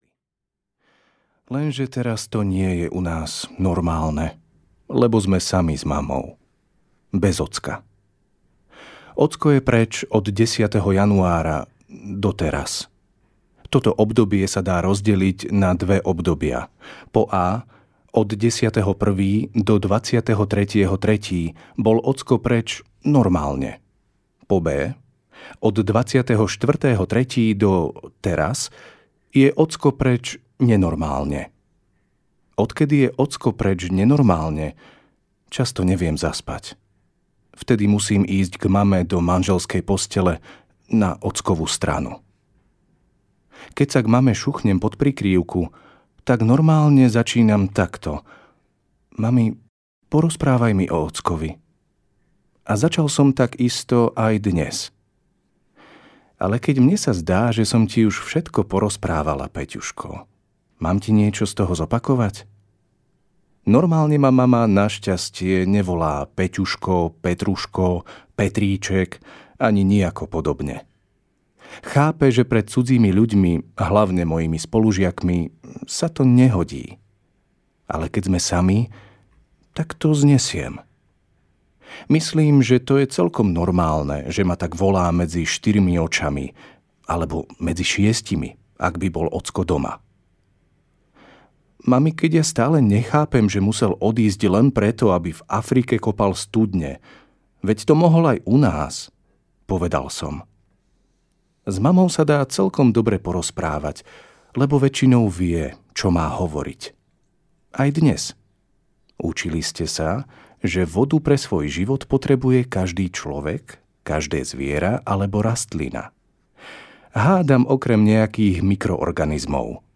Ukázka z knihy
normalny-peter-velmi-tehotna-mama-a-dobry-samaritan-audiokniha